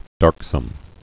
(därksəm)